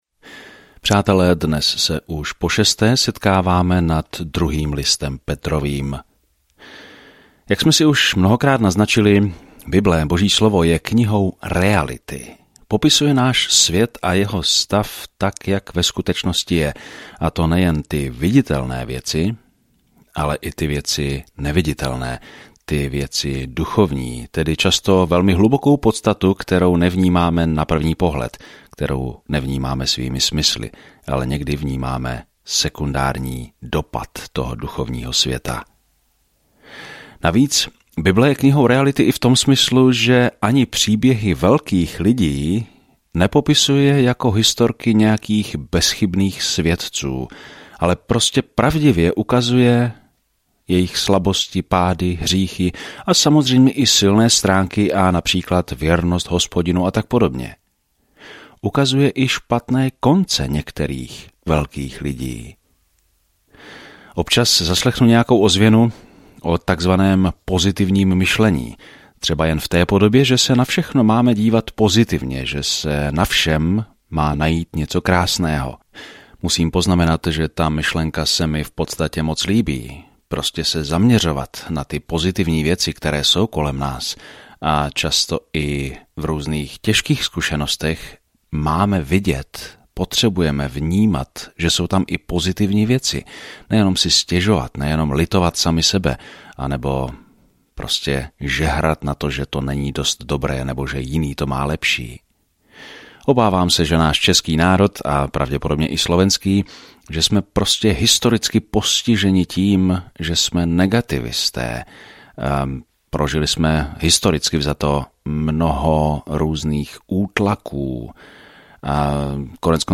Písmo 2 Petr 2:4-8 Den 5 Začít tento plán Den 7 O tomto plánu Druhý Petrův list je celý o Boží milosti – jak nás zachránila, jak nás zachovává a jak v ní můžeme žít – navzdory tomu, co říkají falešní učitelé. Denně procházejte 2. Petra, zatímco budete poslouchat audiostudii a číst vybrané verše z Božího slova.